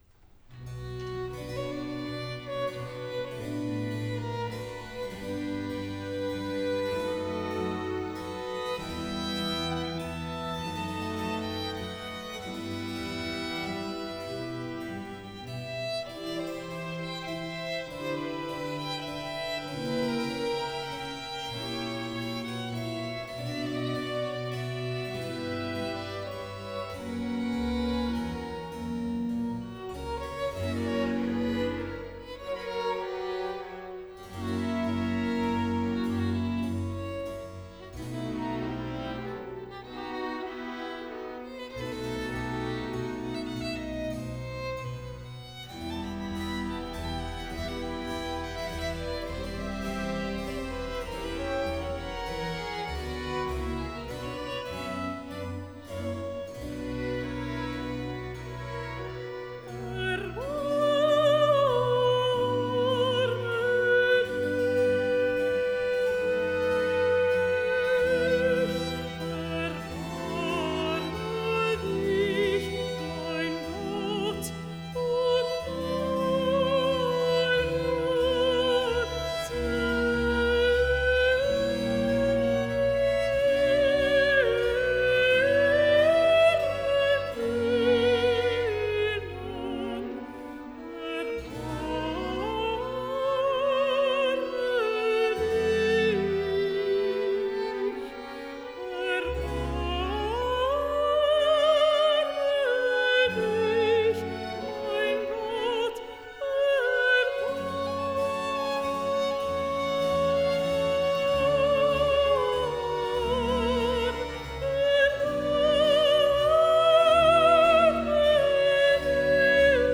enregistrement haute définition en « live »